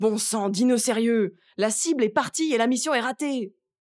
VO_ALL_EVENT_Temps ecoule_03.ogg